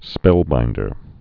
(spĕlbīndər)